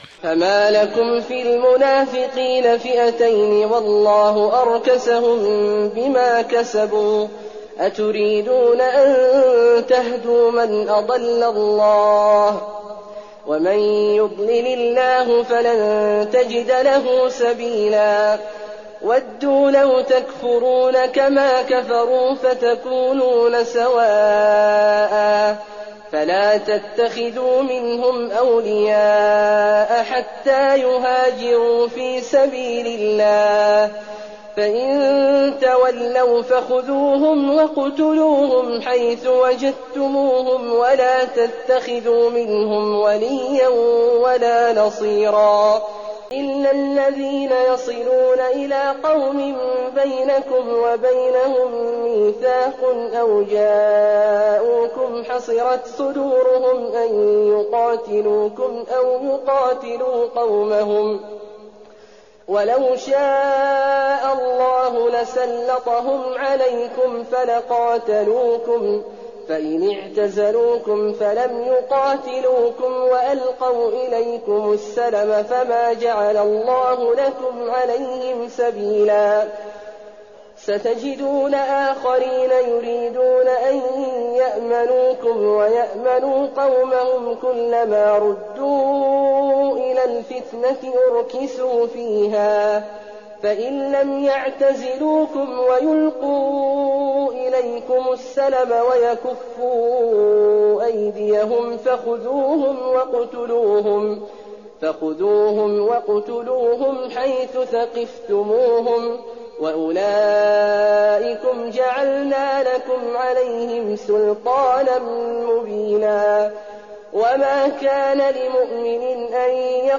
تراويح الليلة الخامسة رمضان 1419هـ من سورة النساء (88-147) Taraweeh 5th night Ramadan 1419H from Surah An-Nisaa > تراويح الحرم النبوي عام 1419 🕌 > التراويح - تلاوات الحرمين